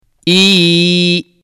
Harflerin üzerine tıklayarak dinleyebilirsiniz UZATAN YE Harekesiz olduğu için okunmaz Kendisinden önceki esreli harfi bir elif miktarı uzatarak okutur